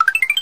level_win.ogg